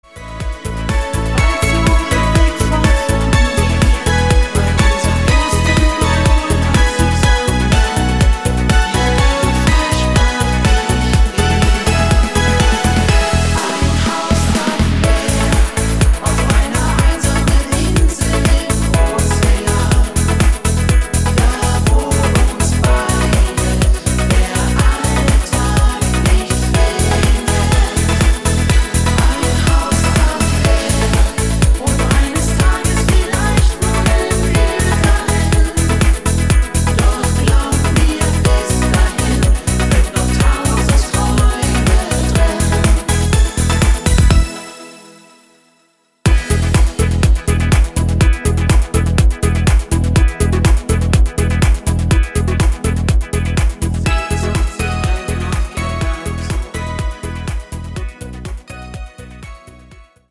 guter neuer Schlagertanztitel Download Buy
Rhythmus  Discofox
Art  Deutsch, Schlager 2020er, Weibliche Interpreten